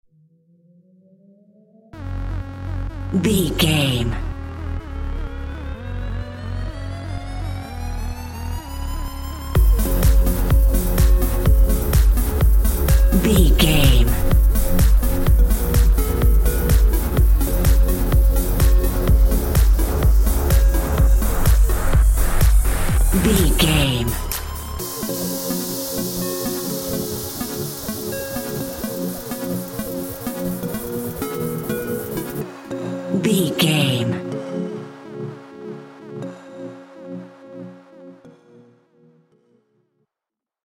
Aeolian/Minor
F#
groovy
dreamy
smooth
futuristic
drum machine
synthesiser
house
electro dance
instrumentals
synth leads
synth bass
upbeat